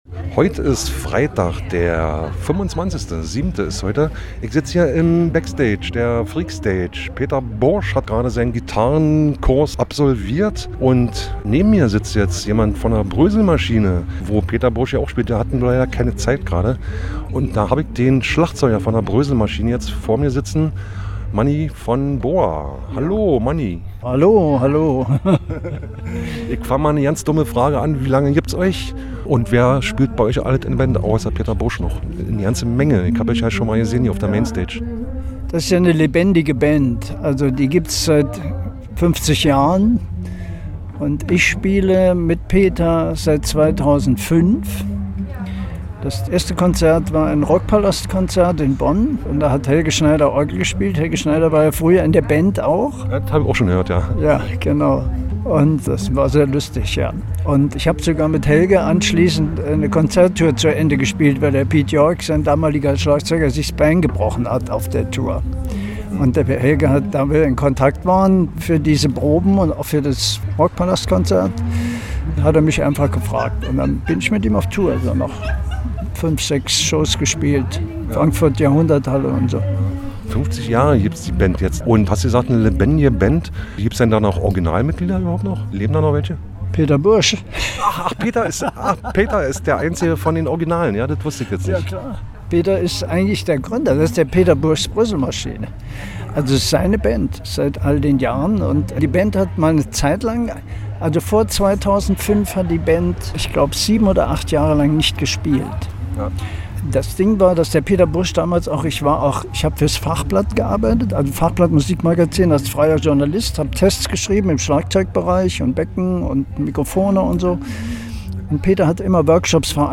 Bandinterviews & szenenews